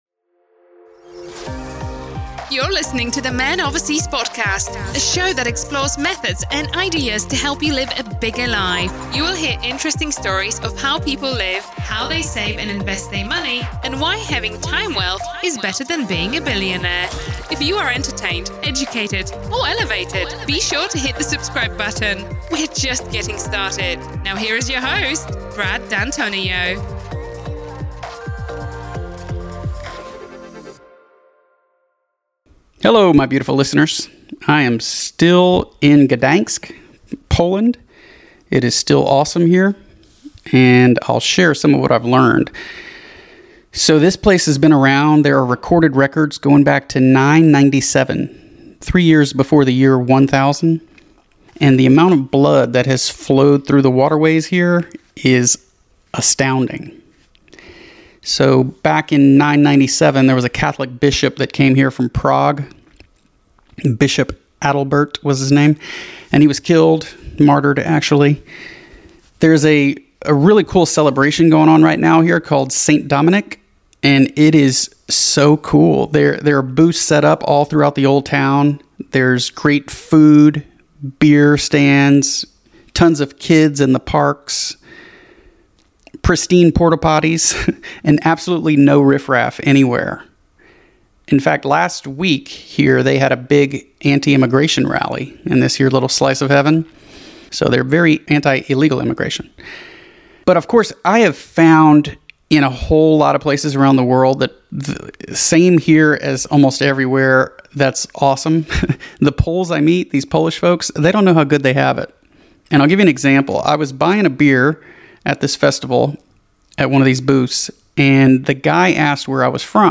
In this short solo episode from the Baltic coast, I talk more about Gdańsk.